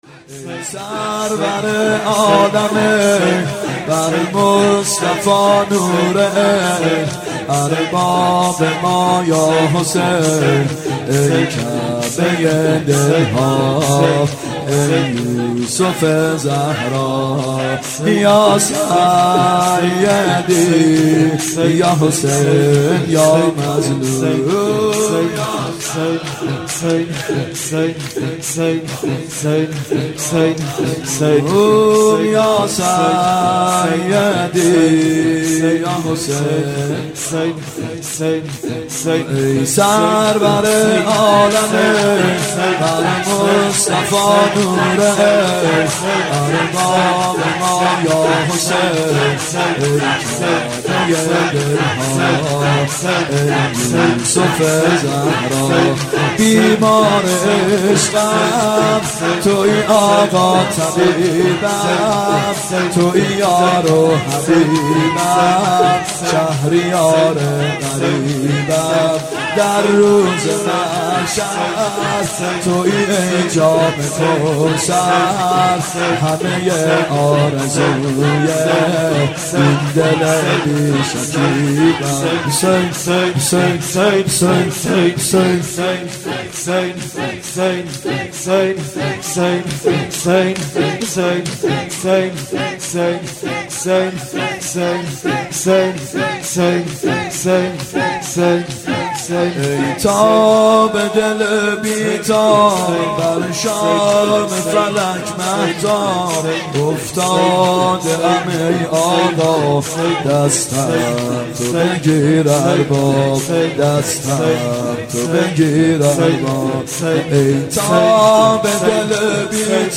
محرو 91 شب ششم شور (ای سرور عالمین بر مصطفی نور عین
محرم 91 ( هیأت یامهدی عج)